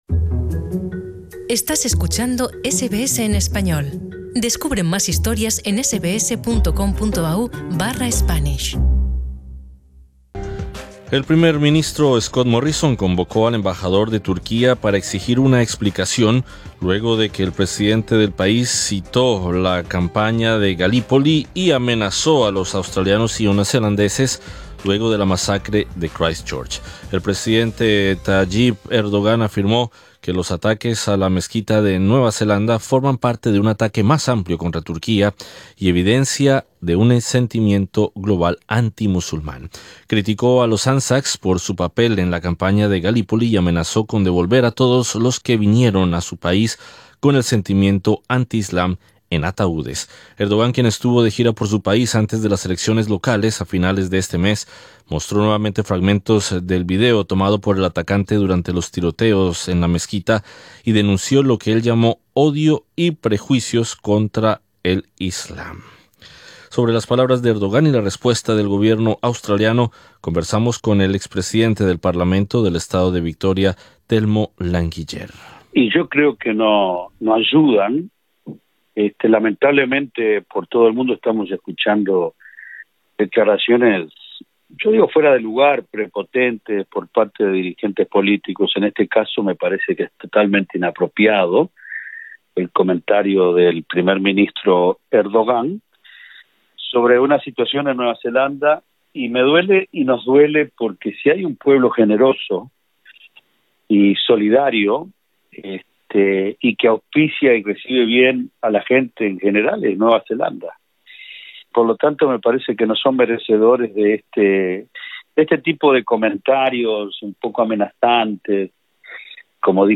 Sobre las palabras de Erdogan y la respuesta del gobierno australiano conversamos con el ex presidente del parlamento del Estado de Victoria, Telmo Languiller. Escucha arriba en nuestro podcast la entrevista.